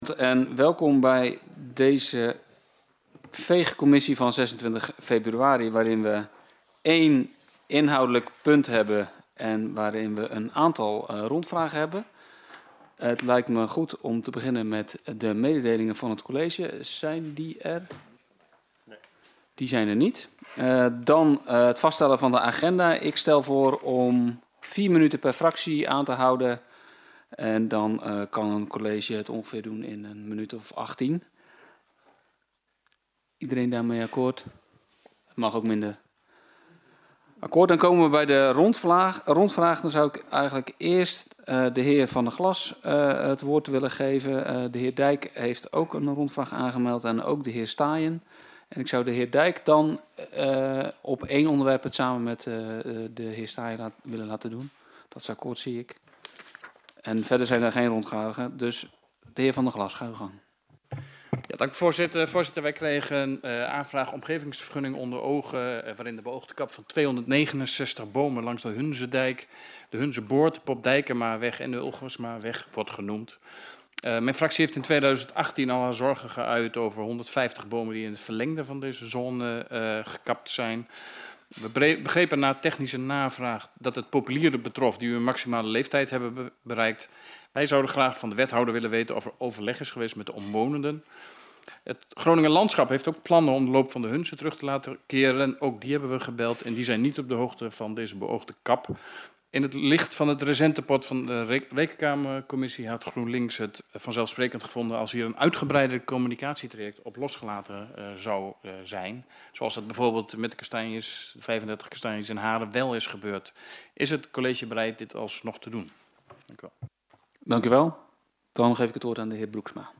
Download de volledige audio van deze vergadering
NB DEZE VERGADERING BEGINT NIET OM 20.00 UUR, MAAR DIRECT NA AFLOOP VAN HET ACTUALITEITENDEBAT DAT OM 20.00 UUR PLAATSVINDT